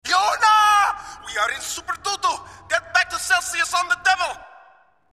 Voice 1 -
Sex: Male